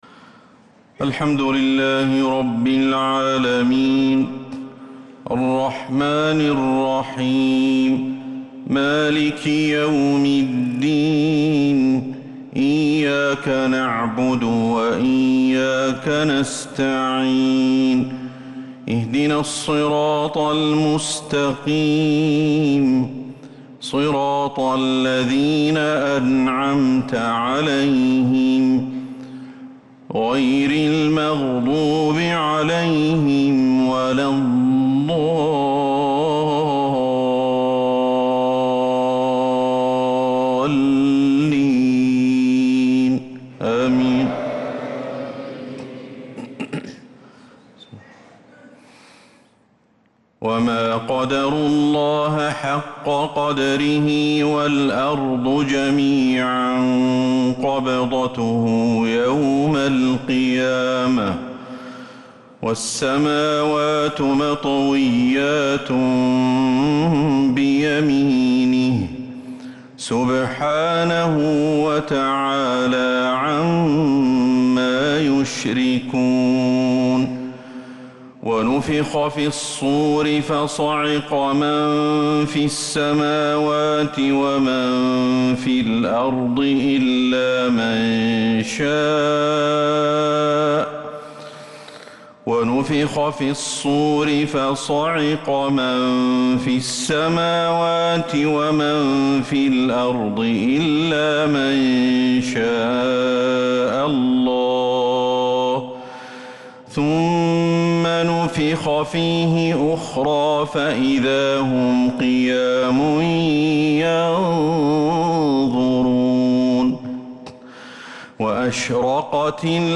عشاء الاثنين 6-7-1446هـ خواتيم سورة الزمر 67-75 | ishaa prayer from surah Az-Zumer 6-1-2025 > 1446 🕌 > الفروض - تلاوات الحرمين